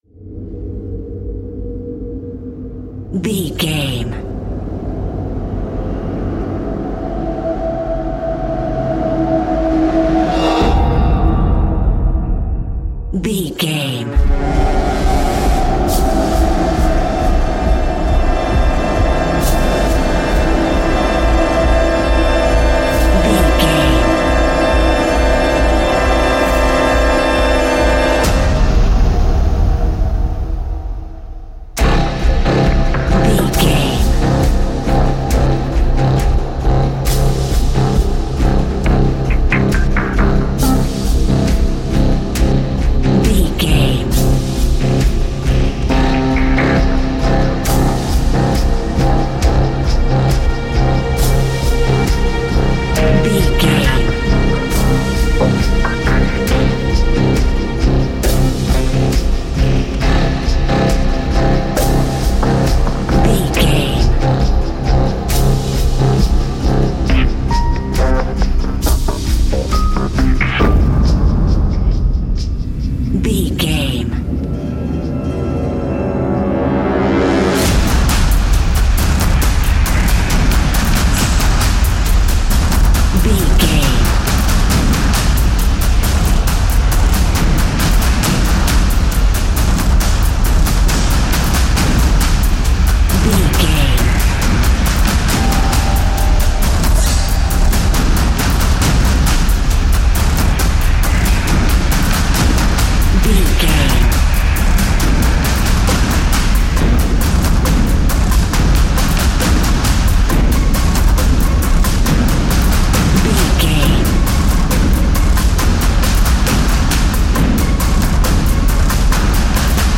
Ionian/Major
C♭
industrial
dark ambient
EBM
experimental
synths